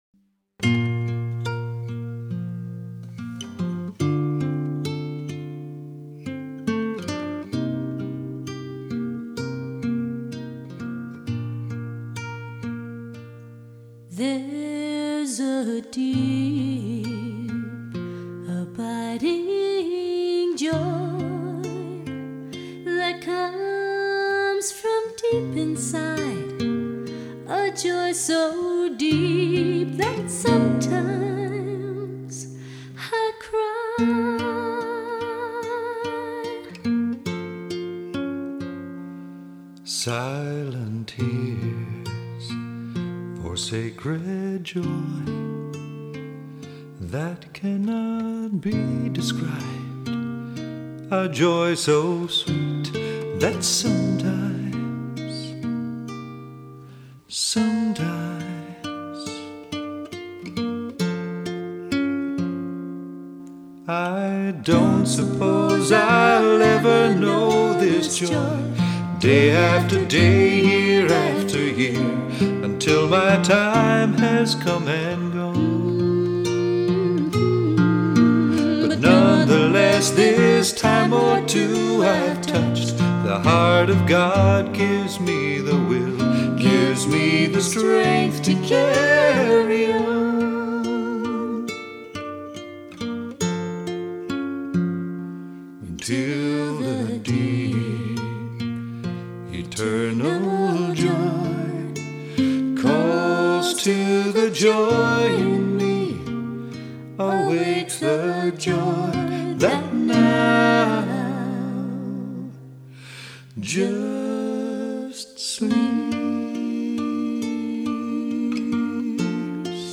free christian music download
on lead guitar